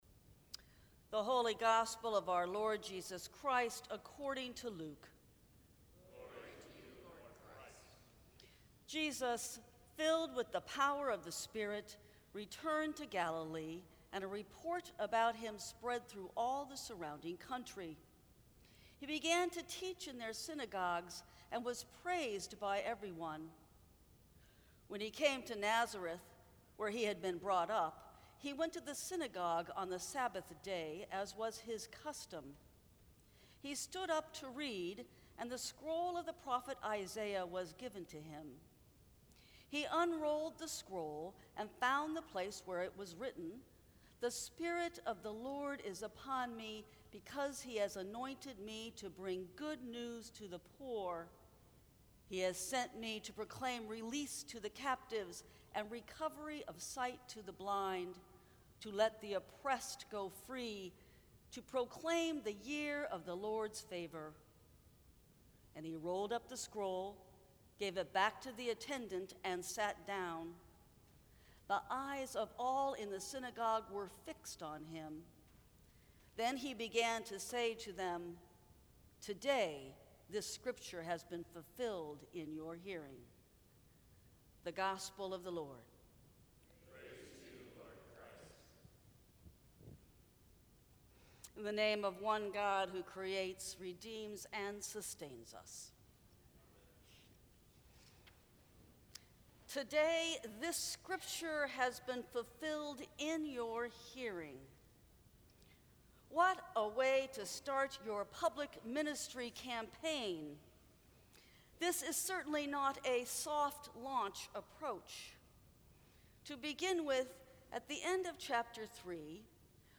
Sermons from St. Cross Episcopal Church Important Campaign Announcement!